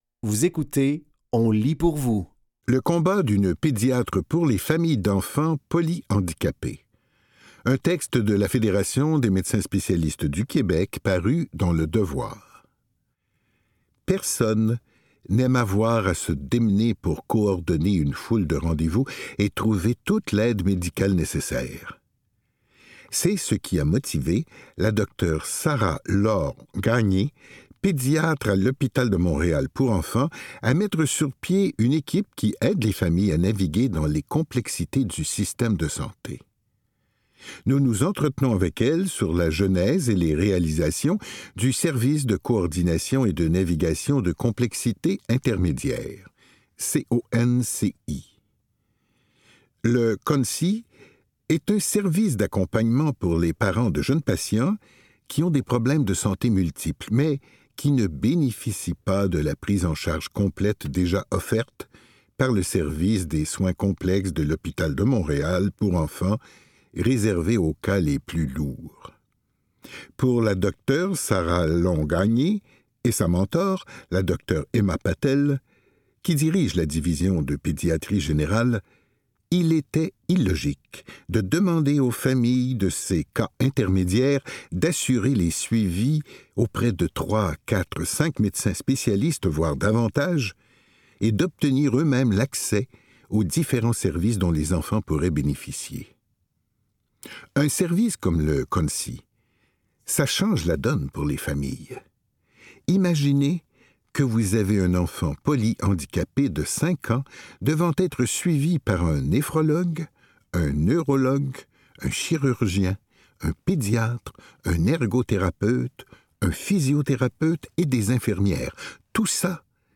Dans cet épisode de On lit pour vous, nous vous offrons une sélection de textes tirés des médias suivants : Le Devoir, TVA Nouvelles, Châtelaine et Le Journal de Montréal. Au programme: Le combat d’une pédiatre pour les familles d’enfants polyhandicapés, un texte de la Fédération des médecins spécialistes du Québec paru dans Le Devoir.…